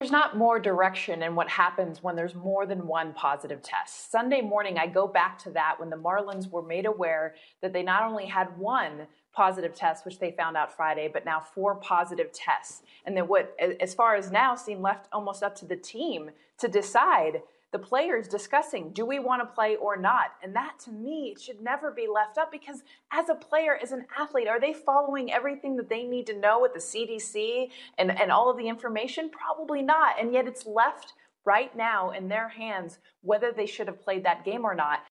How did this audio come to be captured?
(Courtesy of: ABC Newscall)